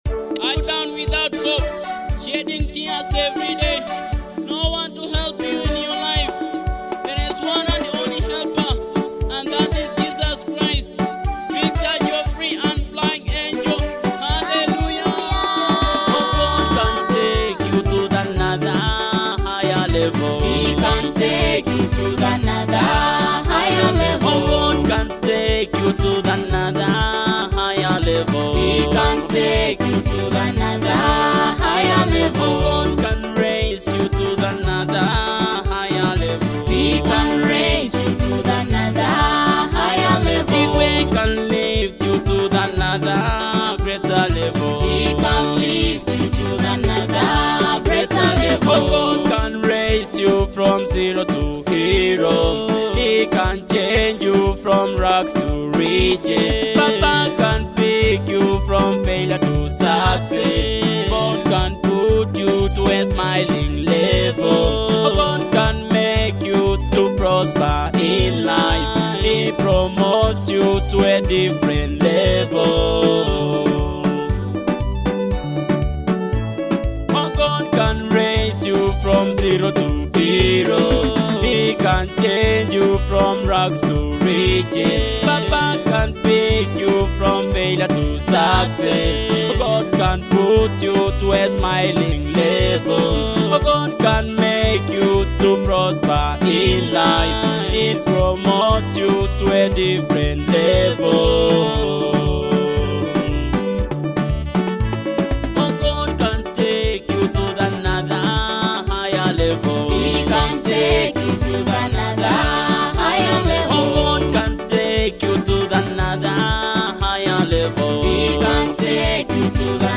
feel the uplifting spirit of this must-have anthem.